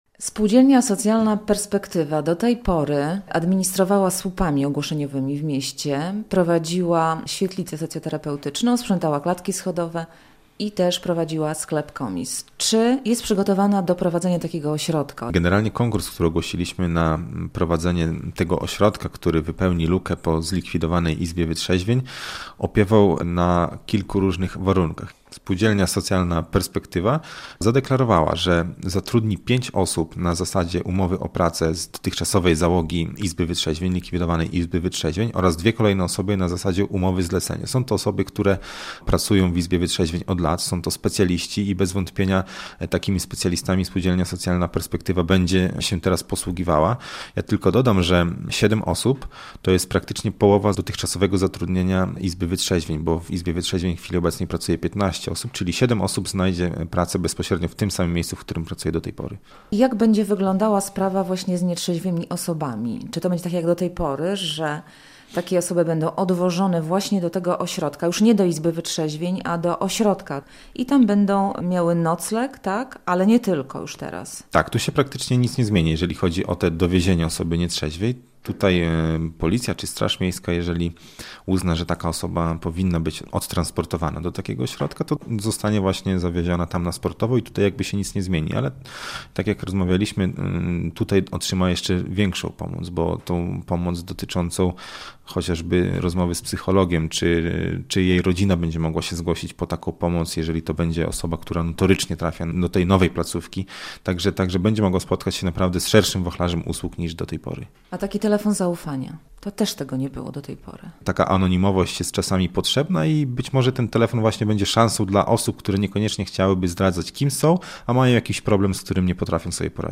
W Suwałkach wznawiają działalność Studenckie Punkty Informacyjne - relacja